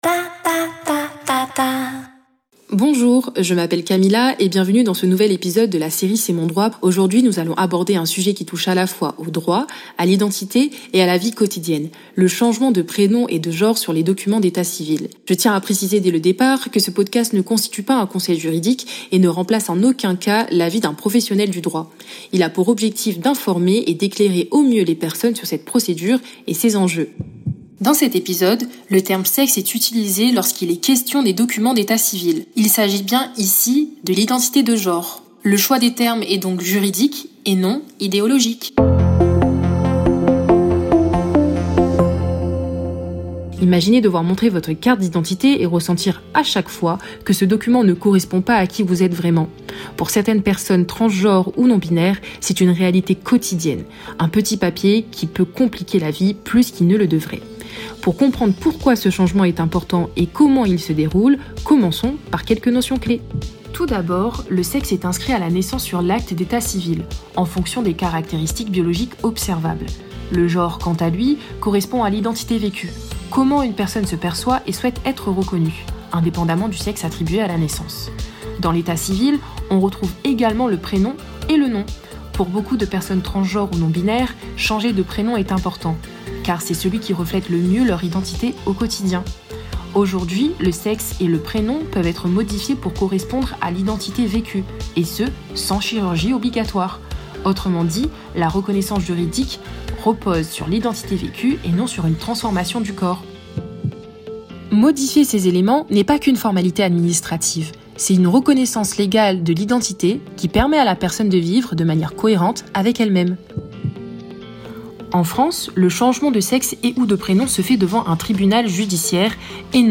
Bienvenue dans C’est mon droit !, le podcast de LA Radio Queer as You qui vous aide à mieux connaître et comprendre les droits des personnes LGBTQIA+.